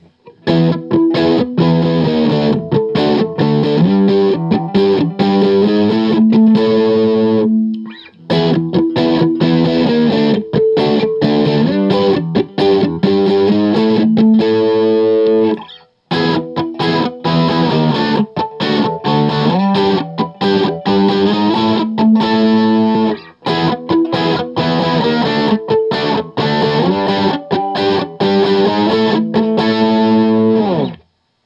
I put the guitar through my usual rig which is the Axe-FX Ultra into the QSC K12 speaker using the Tiny Tweed, JCM 800, and Backline settings.
J800 A Barre Chords
Each recording goes though all of the pickup selections in the order: neck, both (in phase), both (out of phase), bridge.